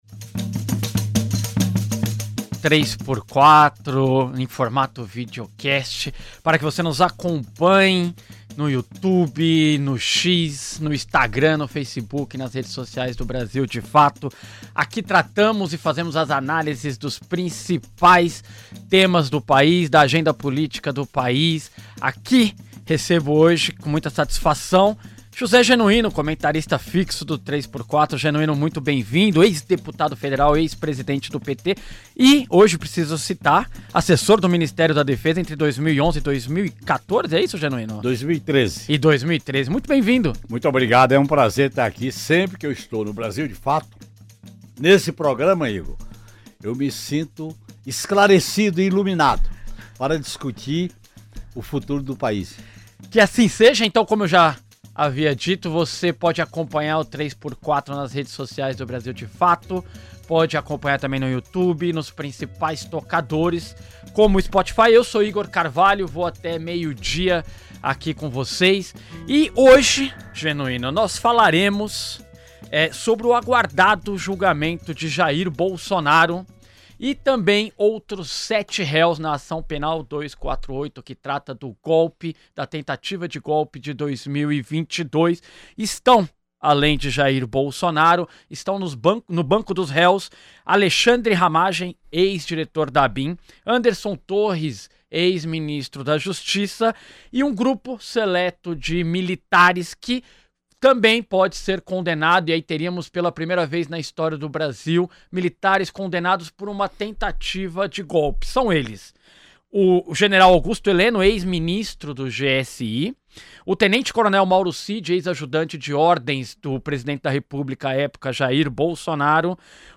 Em entrevista ao podcast Três por Quatro